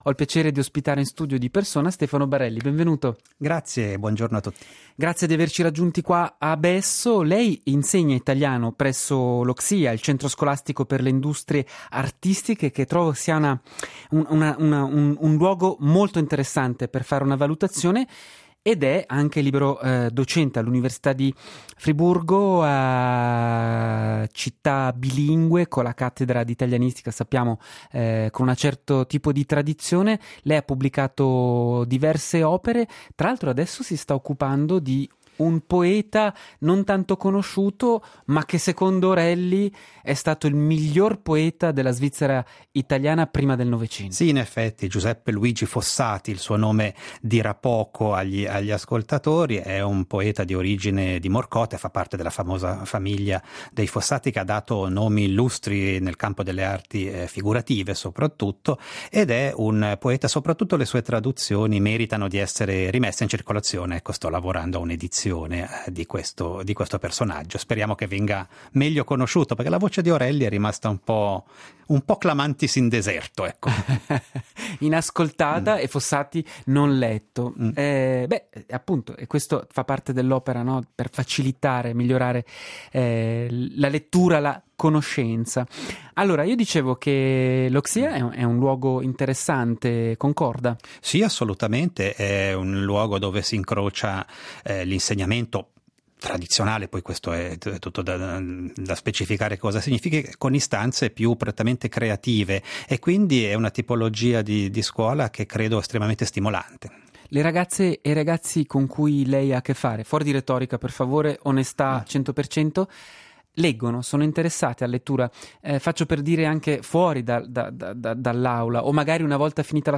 Lettura: la salute